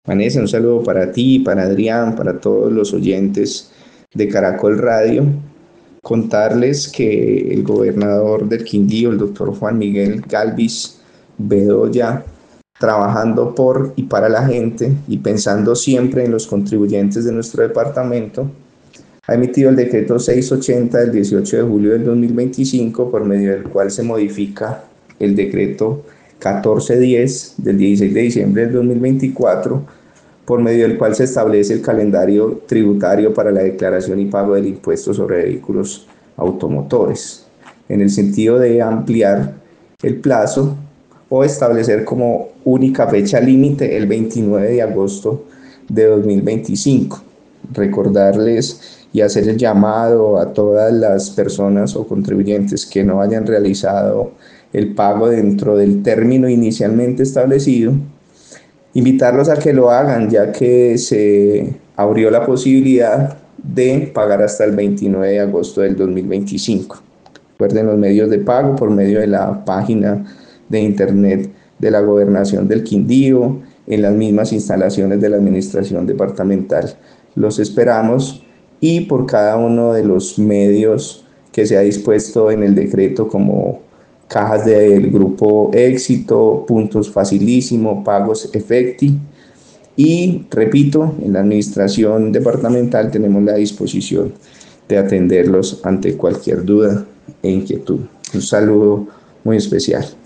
Director tributario de la gobernación del Quindío, Mauricio Olarte